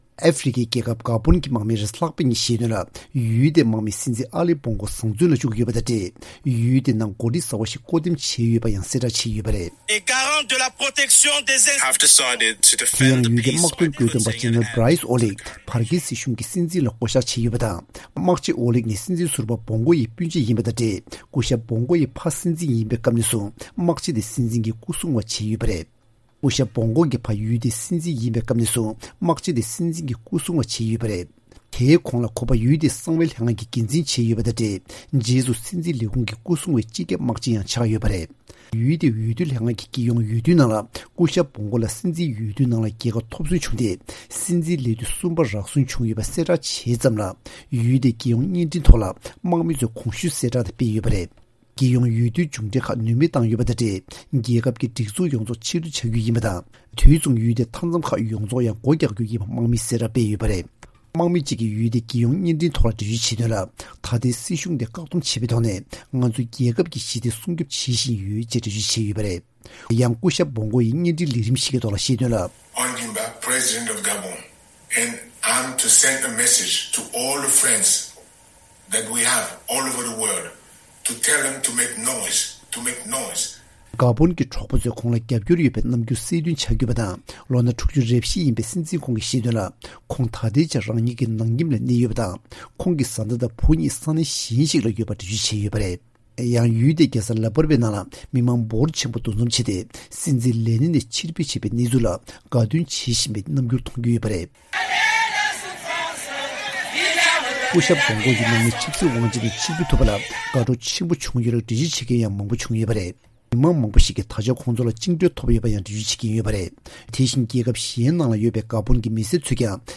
གནས་ཚུལ་སྙན་སྒྲོན་ཞུ་ཡི་རེད།